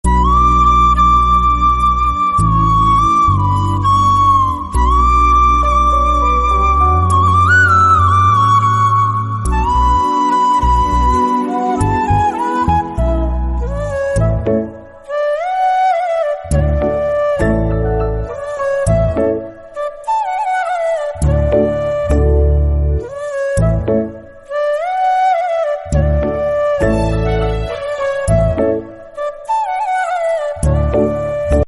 best flute ringtone download
melody ringtone romantic ringtone